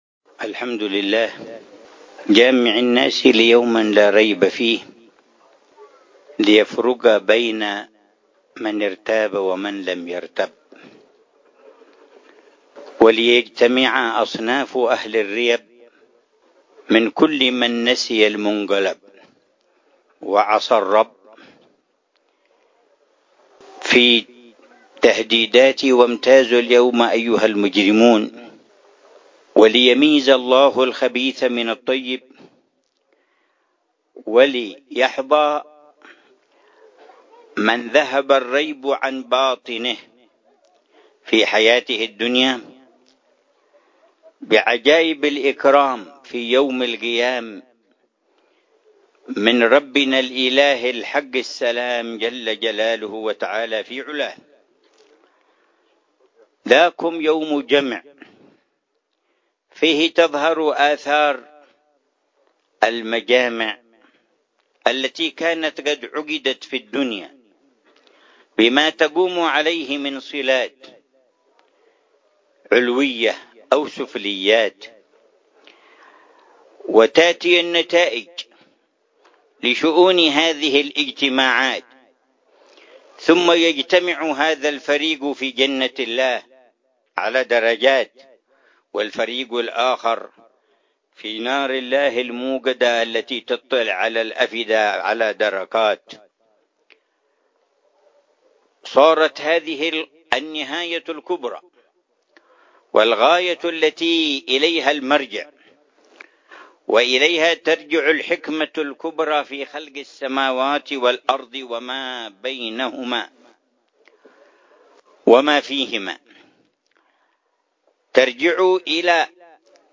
مذاكرة العلامة الحبيب عمر بن محمد بن حفيظ في ديوان معالي جمال الصرايرة في مؤتة، الكرك، الأردن، ليلة الأحد 15 جمادى الآخرة 1446هـ ، بعنوان: